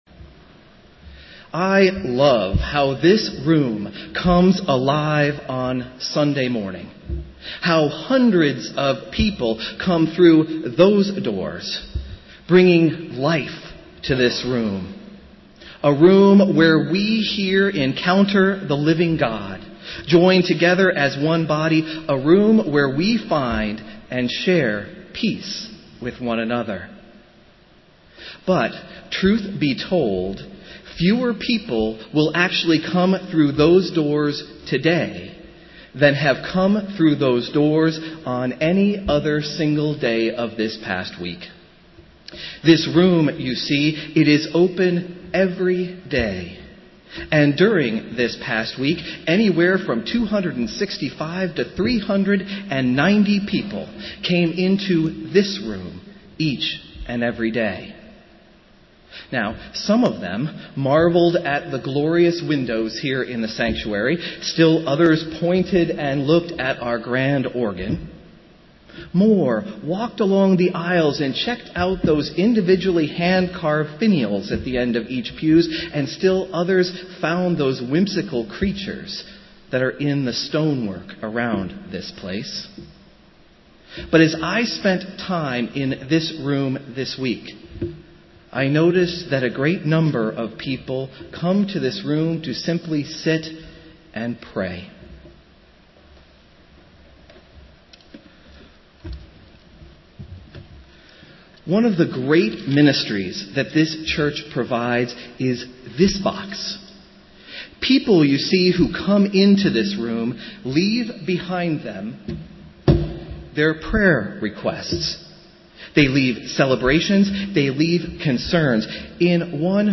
Festival Worship - Twelfth Sunday after Pentecost